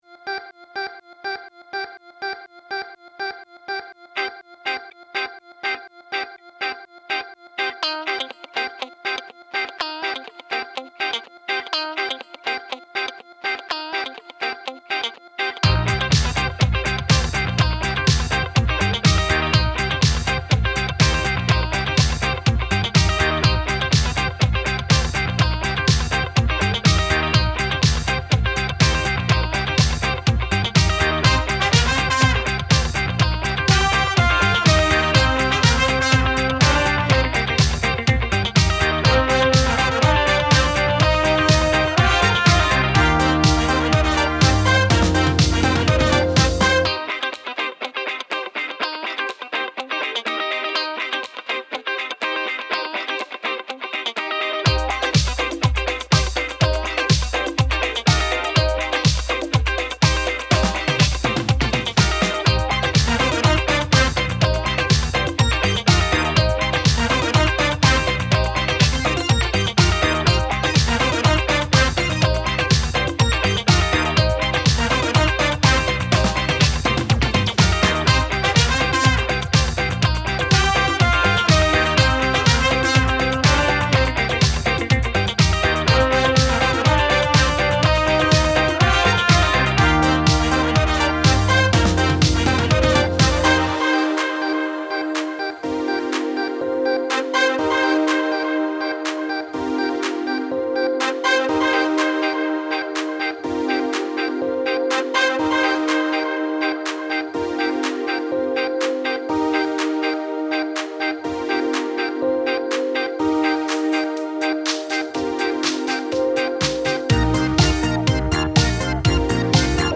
Nu-Disco/Indie нужны советы